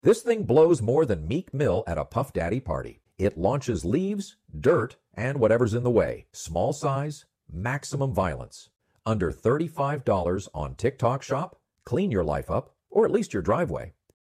Cordless leaf blower that's perfect